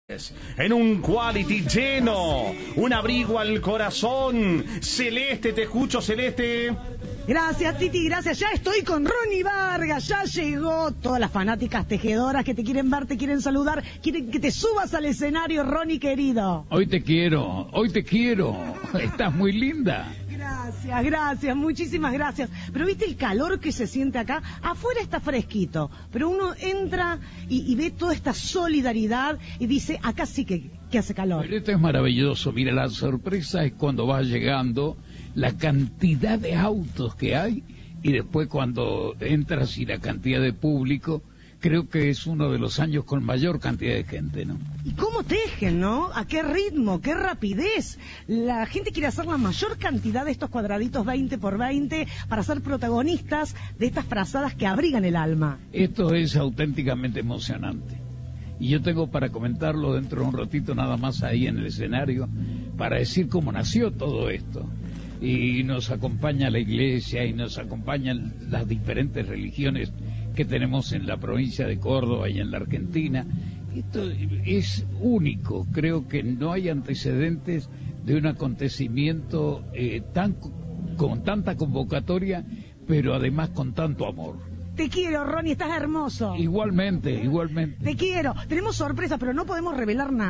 Cientos de personas se congregaron este sábado en el Quality Arena de la ciudad de Córdoba, en el marco de una nueva edición de "Un abrigo al corazón", un evento solidario organizado por el COMIPAZ, la Municipalidad de Córdoba, el Gabinete Social del Gobierno provincial y Cadena 3 Argentina.
Cerca del mediodía, el locutor histórico de Cadena 3, Rony Vargas, se hizo presente en el lugar y se mostró emocionado por la cantidad de gente y aseguró que se trató de una de las ediciones más convocantes.